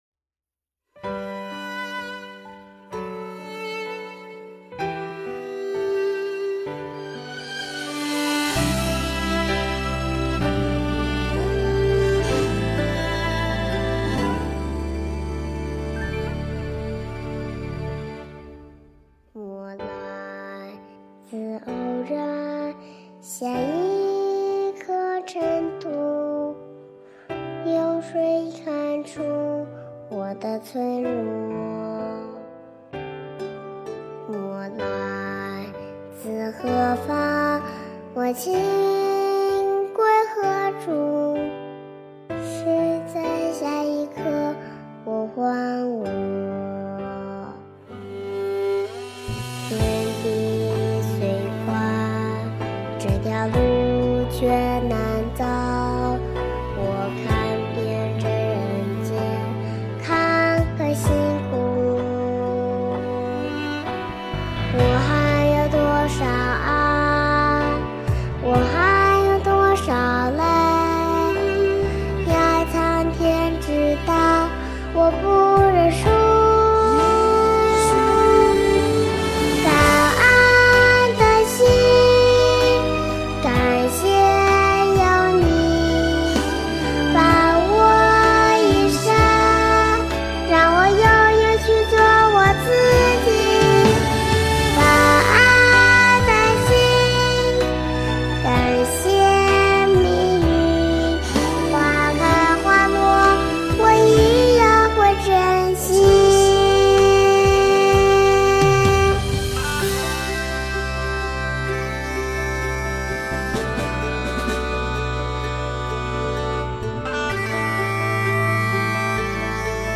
音频：紐約布魯克林觀音堂母親節活動花絮2023年05月30日